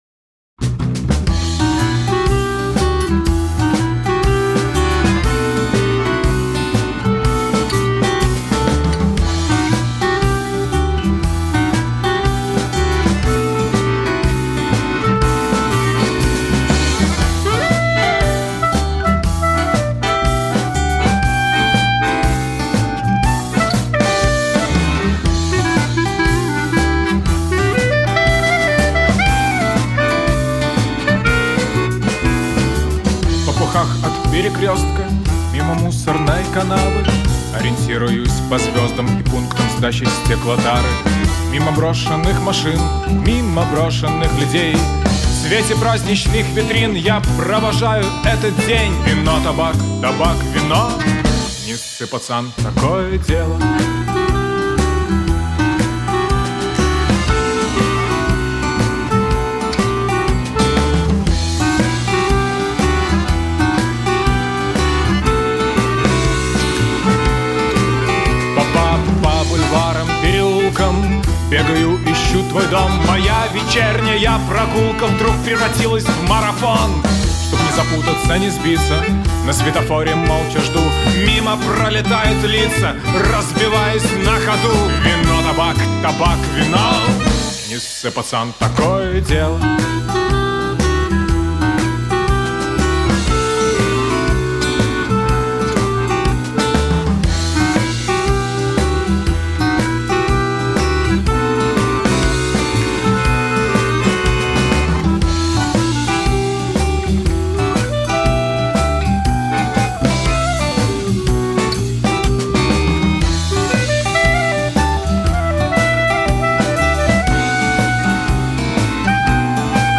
Цыганский рок из Бреста.